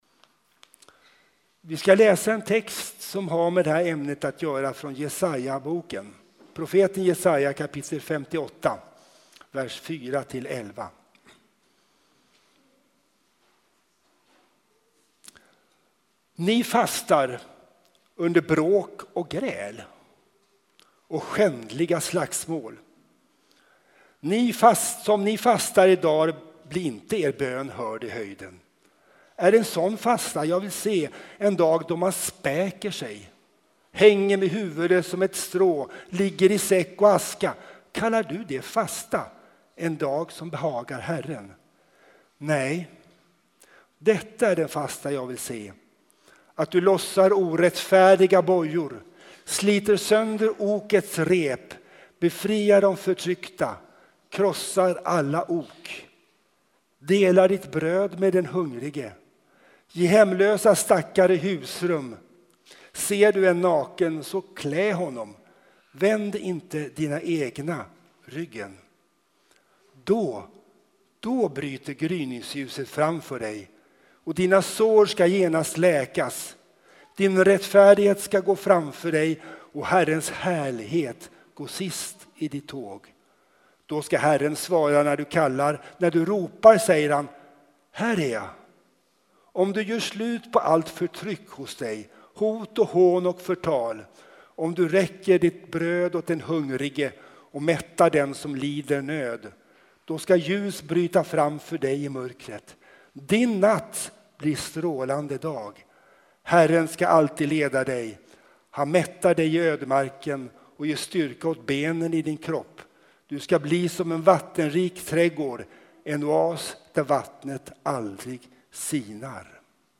Textläsning, predikan och bönJesaja 58:4-11, Matt 25:31-46, Rom 8:31-38